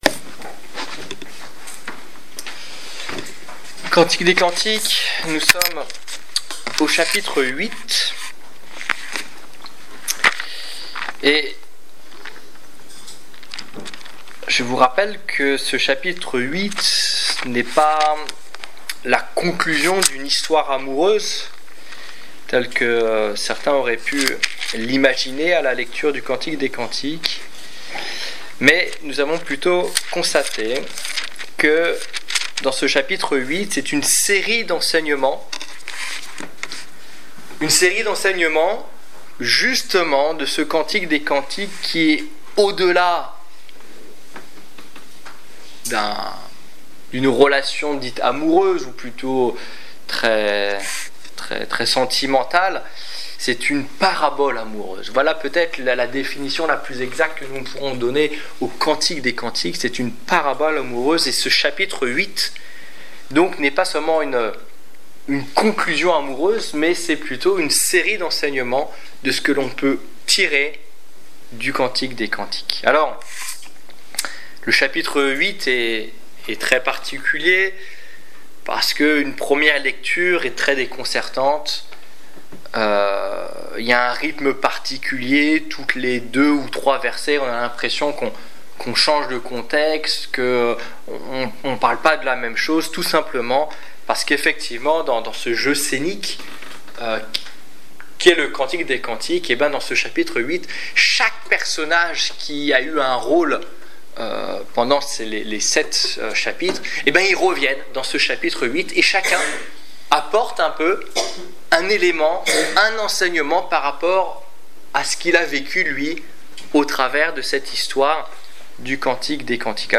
Étude biblique du 21 octobre 2015